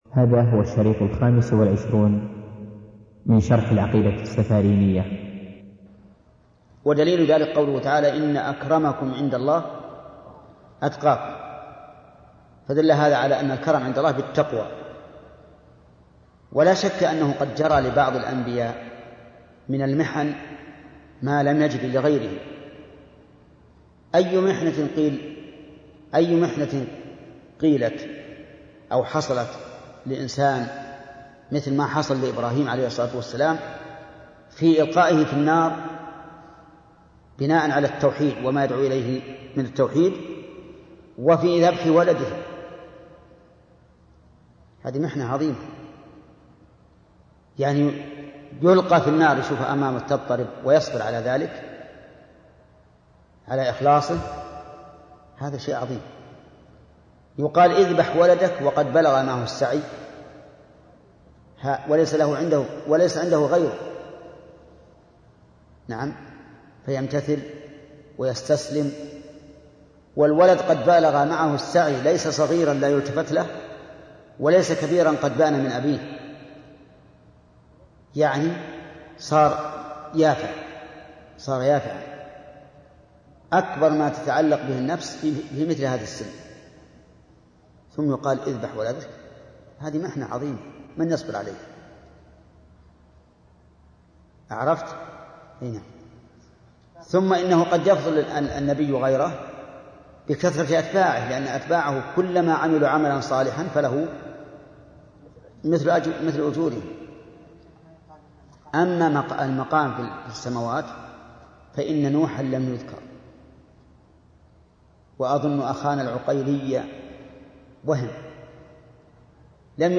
تفاصيل المادة عنوان المادة الدرس الخامس والعشرون تاريخ التحميل الأربعاء 2 اكتوبر 2013 مـ حجم المادة 42.86 ميجا بايت عدد الزيارات 719 زيارة عدد مرات الحفظ 316 مرة إستماع المادة حفظ المادة اضف تعليقك أرسل لصديق